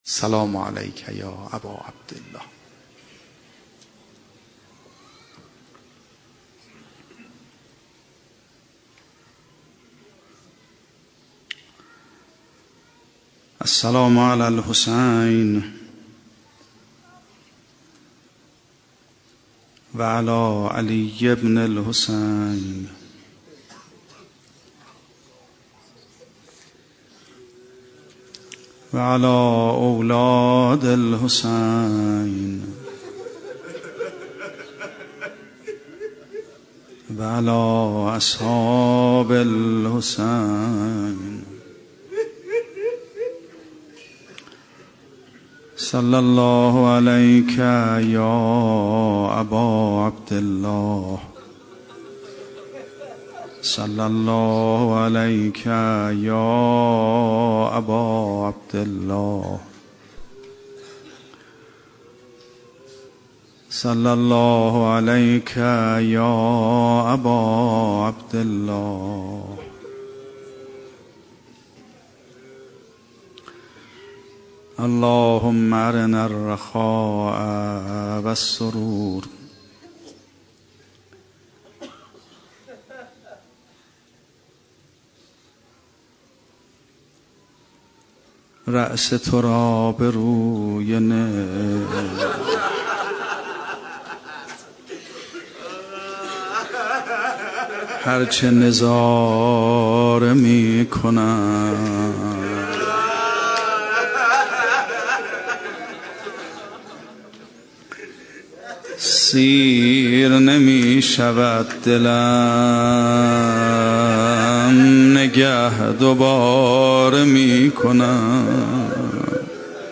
16 مهر 96 - هیئت میثاق با شهدا - روضه